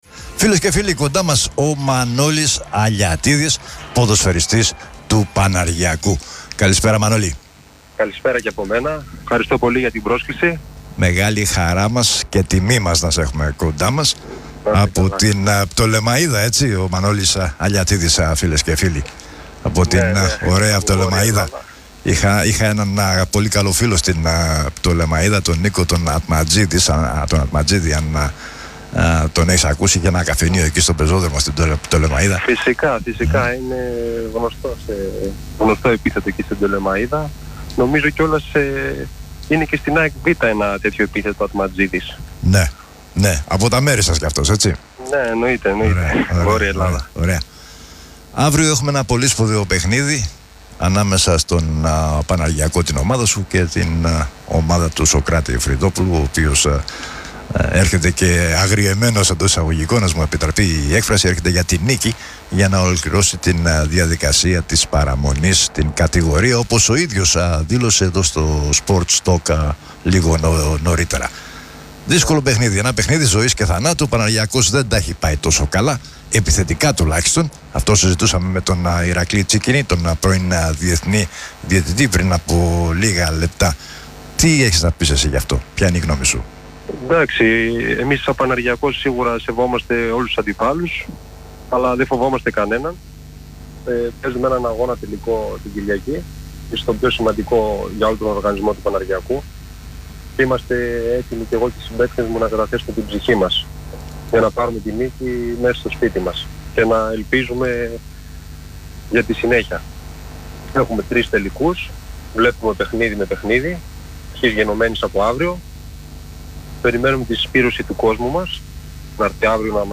μίλησε στη χθεσινή ραδιοφωνική εκπομπή